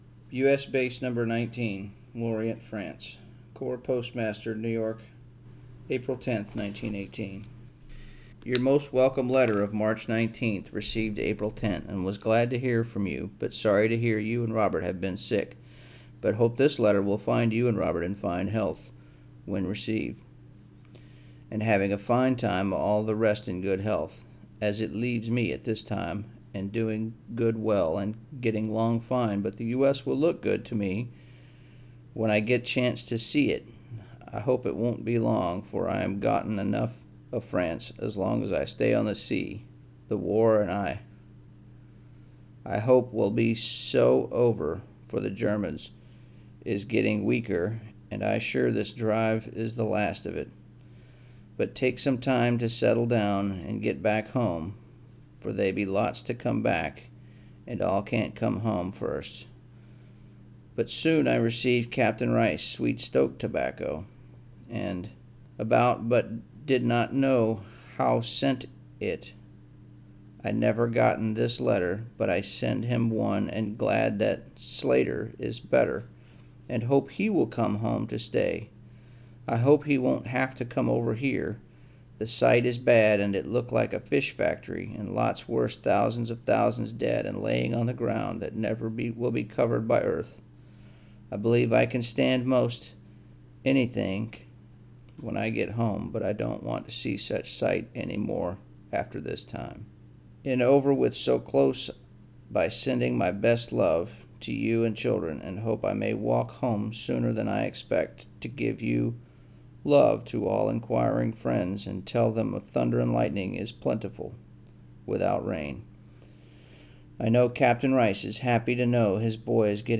We are truly honored to have local U.S. military veterans from different backgrounds read portions of the Letters Home Collection from The Mariners’ Museum Library.